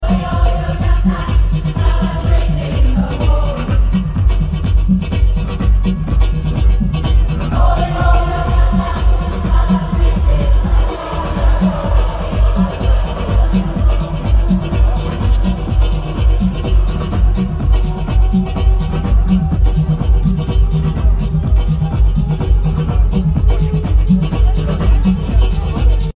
with a stunning set in the Jordan desert